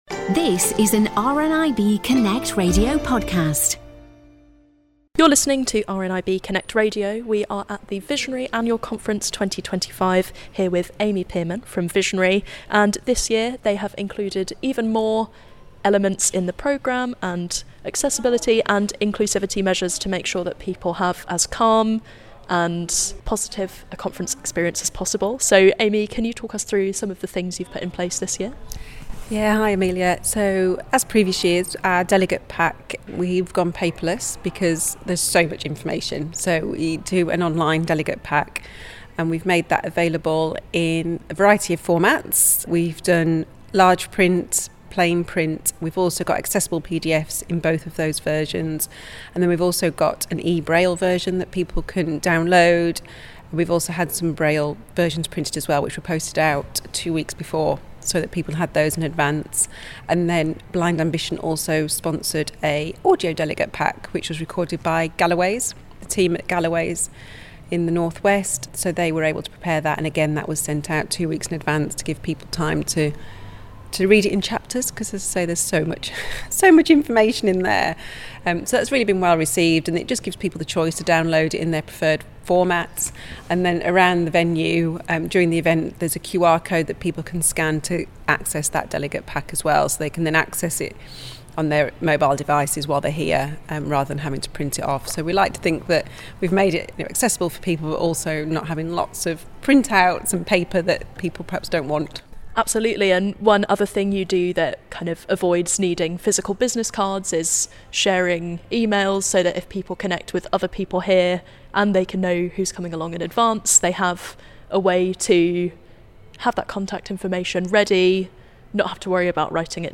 Learn more about Visionary on their website - Welcome to Visionary - Visionary Image shows the RNIB Connect Radio logo.